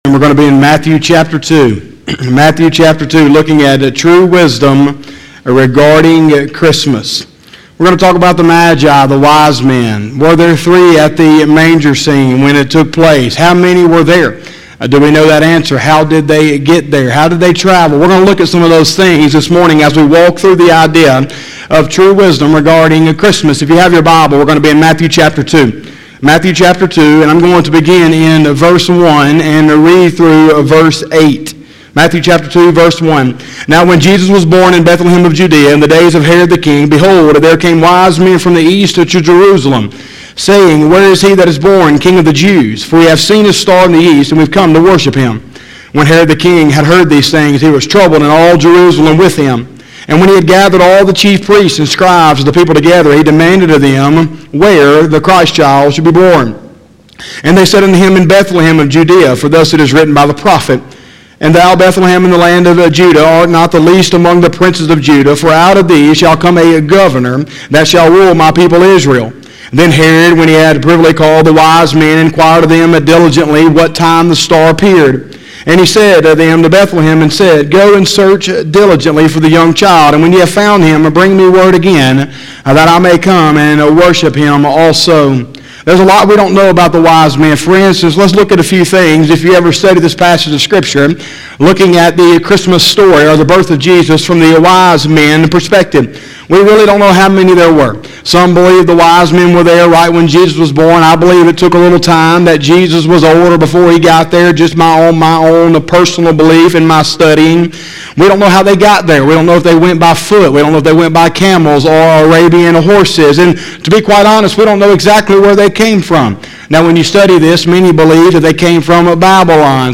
12/06/2020 – Sunday Morning Service